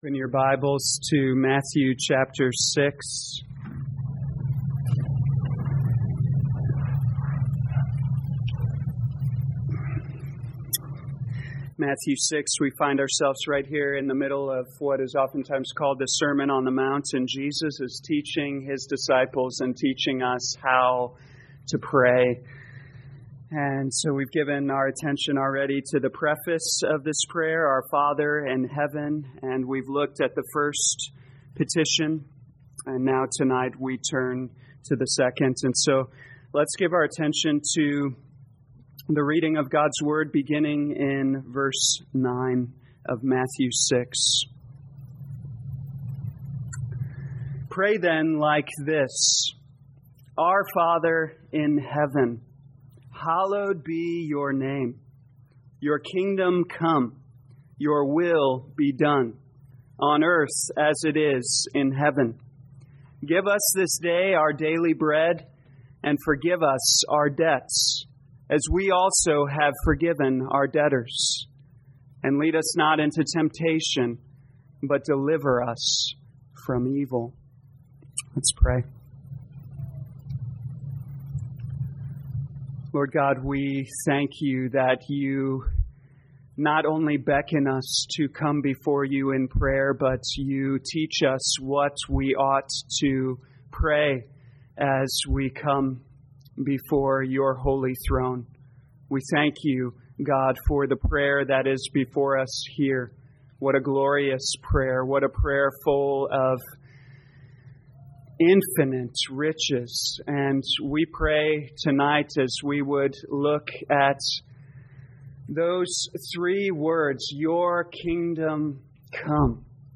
2021 Matthew Evening Service Download
Audio All sermons are copyright by this church or the speaker indicated.